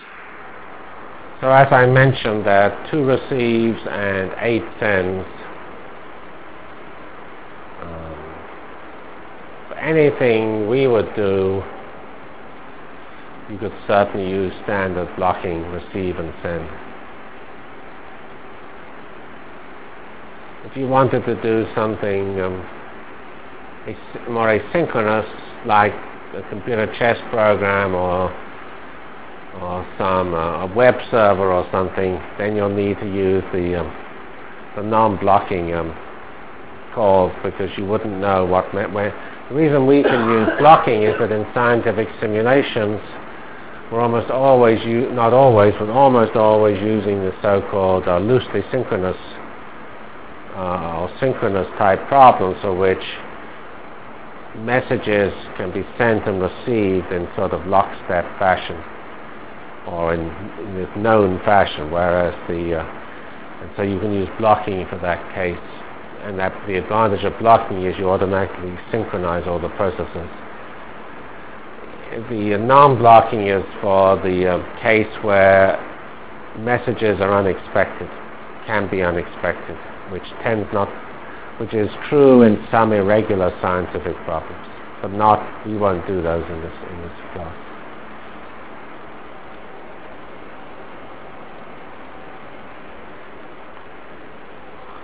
From CPS615-Completion of MPI foilset and Application to Jacobi Iteration in 2D Delivered Lectures of CPS615 Basic Simulation Track for Computational Science -- 7 November 96. by Geoffrey C. Fox *